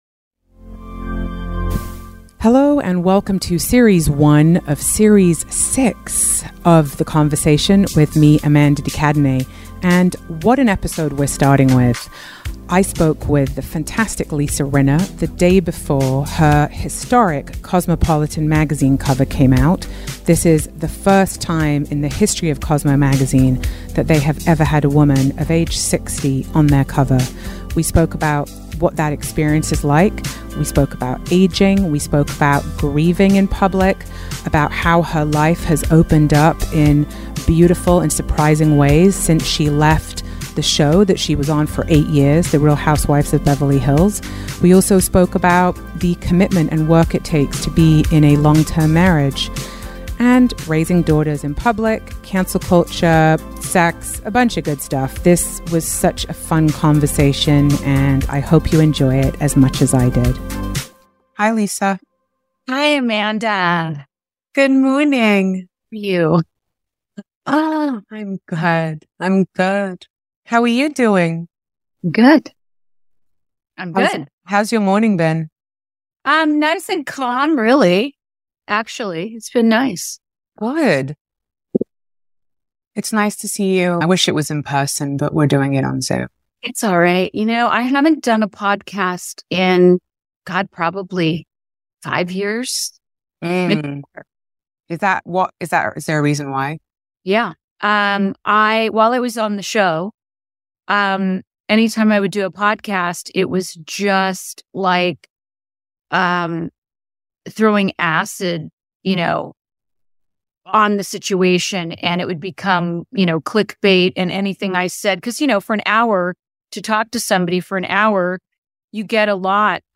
In this podcast episode, Lisa Rinna and Amanda de Cadenet engage in a vulnerable discussion about the complexities of grief, parent…
Play Rate Listened List Bookmark Get this podcast via API From The Podcast Amanda de Cadenet is inviting men onto her award winning interview series, The Conversation.